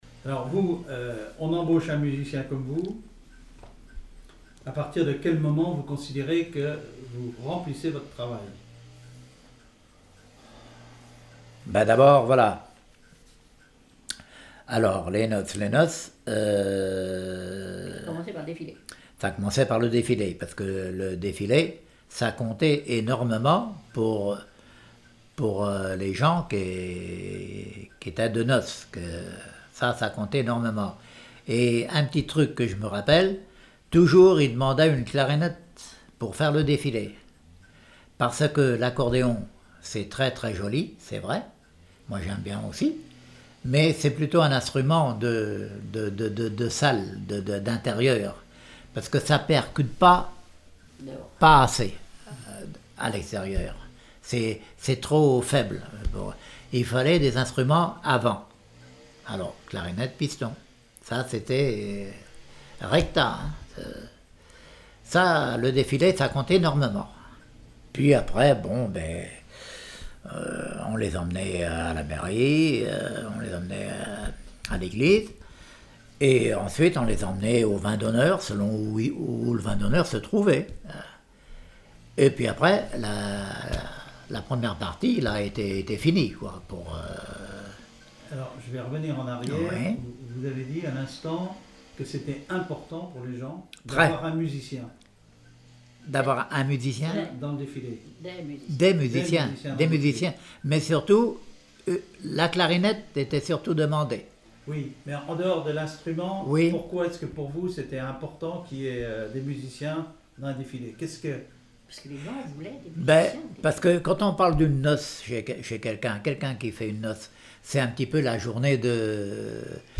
Témoignage comme joueur de clarinette
Catégorie Témoignage